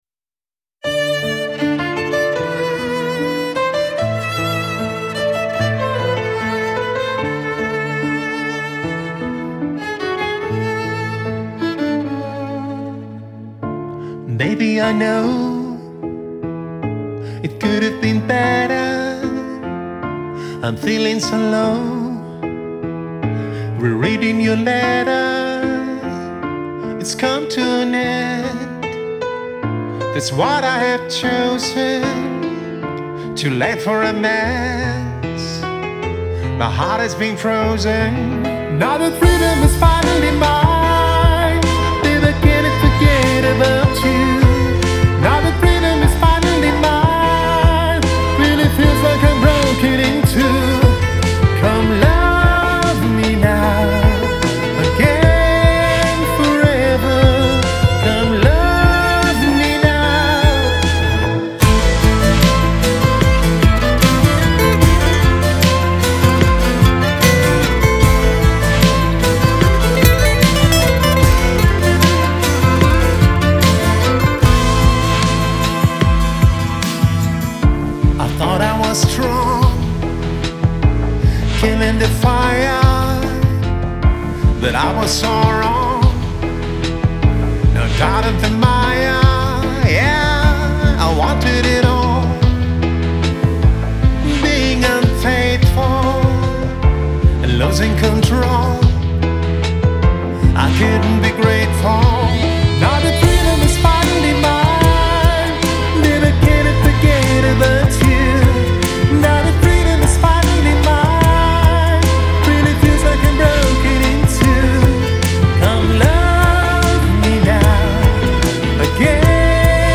выполненная в жанре поп-рок с элементами электронной музыки.